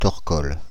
Ääntäminen
France (Île-de-France): IPA: /tɔʁ.kɔl/